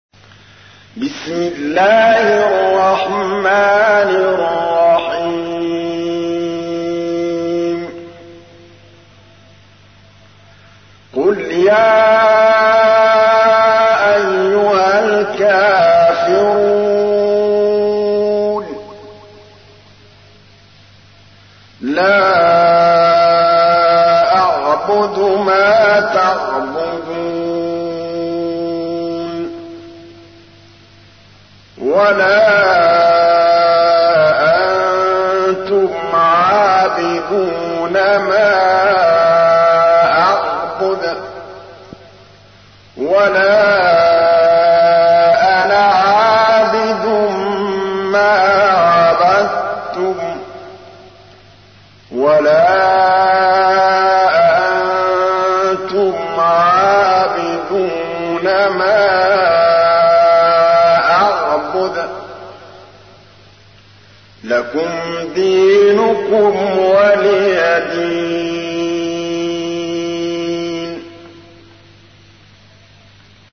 تحميل : 109. سورة الكافرون / القارئ محمود الطبلاوي / القرآن الكريم / موقع يا حسين